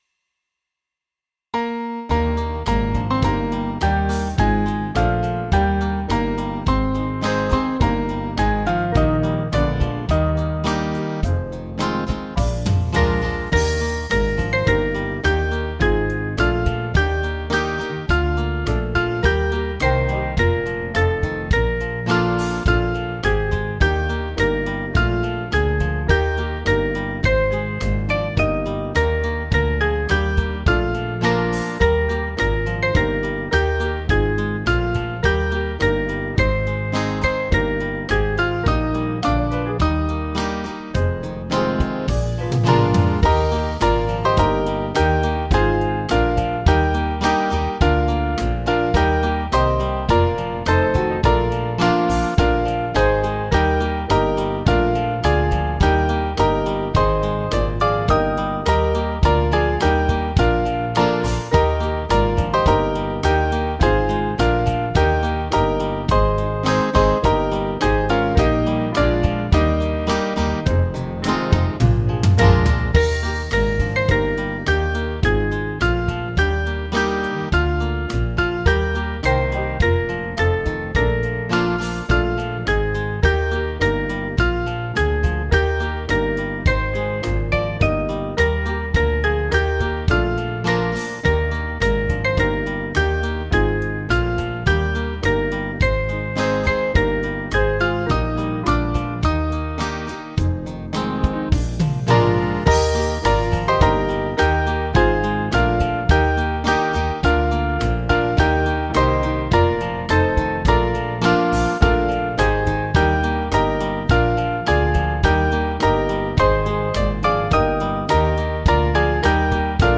Small Band
4/Eb 478.9kb